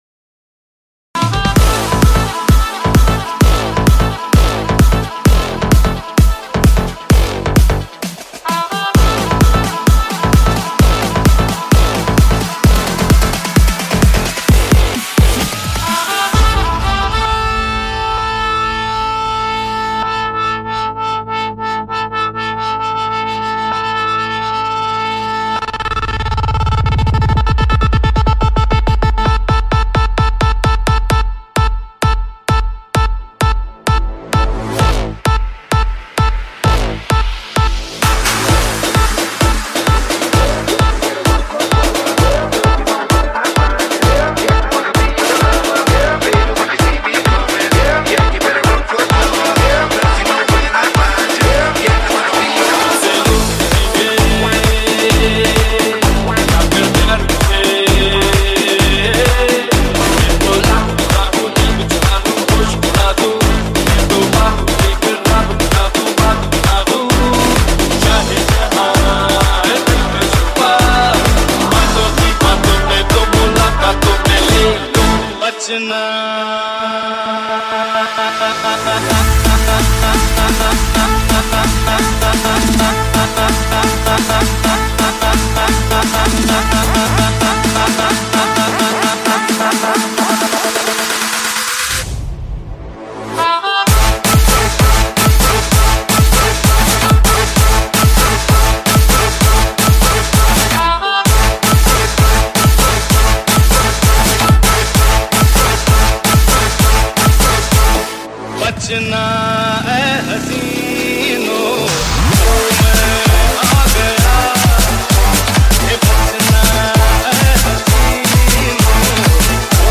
Bollywood Club Song